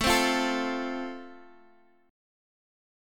Listen to Am#5 strummed